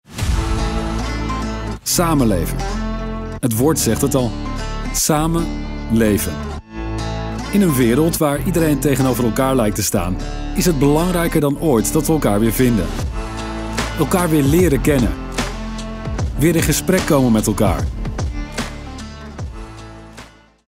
Politische Anzeigen
Niederländische Sprecherstimme: Business, Zuverlässig & Positiv
Meine Stimme ist geschäftlich, zugleich zugänglich, zuverlässig und positiv.
Ich nehme in meinem eigenen professionellen Heimstudio auf, um erstklassigen, sendetauglichen Sound zu liefern.
Mikrofon: Neumann TLM 103
Kabine: Studioguys Akustik-Isolationskabine (trockener Sound)
VertrauenswürdigKonversationFreundlichWarmErfahrenZuverlässigUnternehmenPositiv